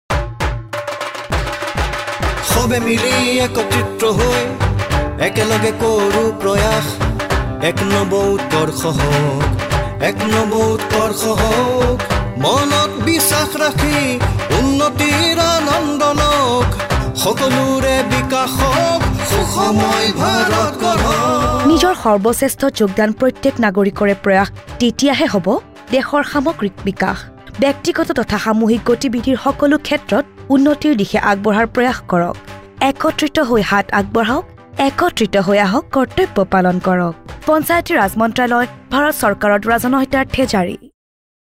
34 Fundamental Duty 10th Fundamental Duty Strive for excellence Radio Jingle Assamese